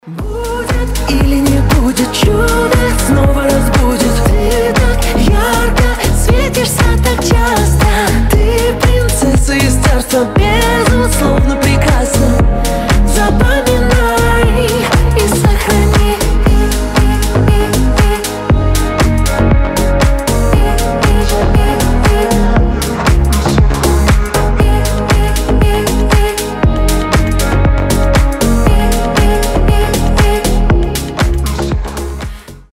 • Качество: 320, Stereo
красивый мужской голос